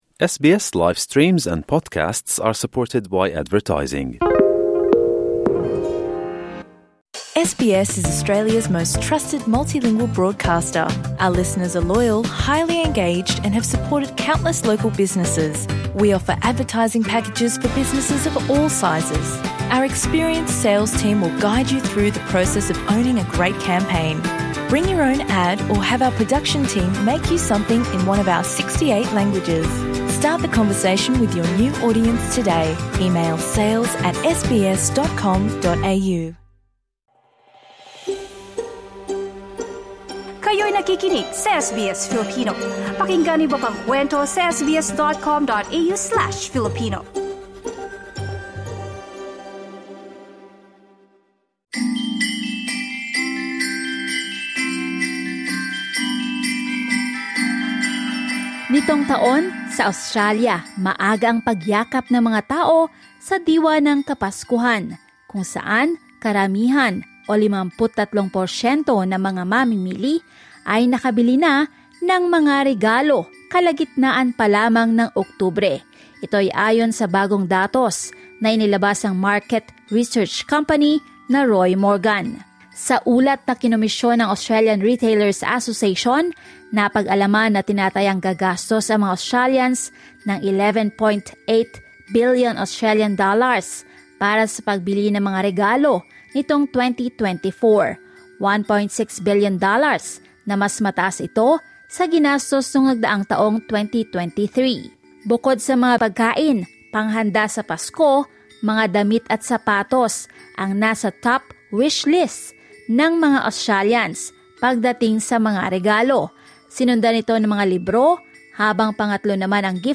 A number of Filipinos in Australia share what they wish for this Christmas Credit: SBS Filipino